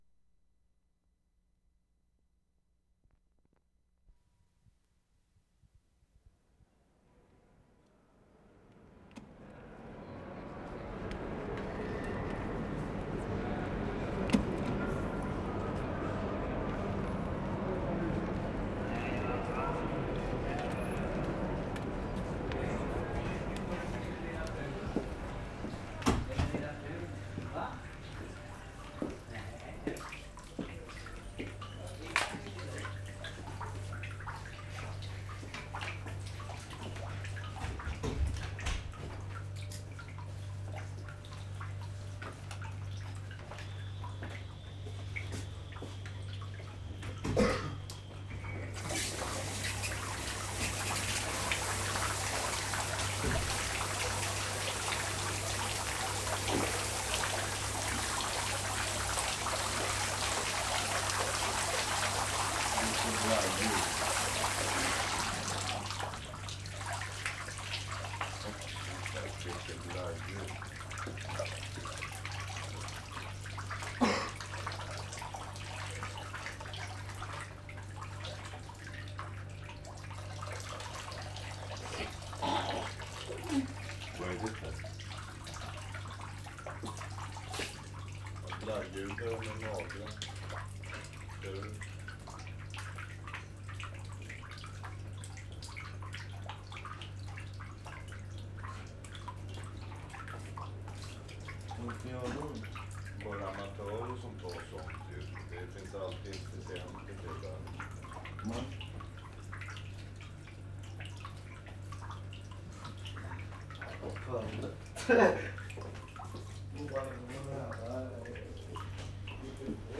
Stockholm, Sweden Feb. 10, 12/75
CENTRAL STATION - from men's room to train platform.
mark * out of men's room into waiting room. [1:54]
mark * door slamming sequence begins. [3:49]
mark * whistle marks beginning of train departure sequence. [5:10]
1. Constant ambience of dripping water and low level electric hum in men's room. Occasional voice fragments.